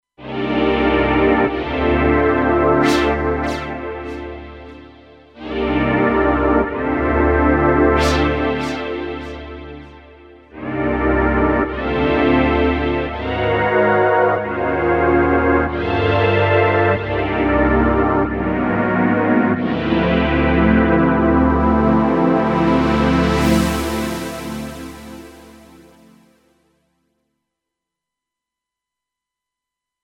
Patch 100 PAD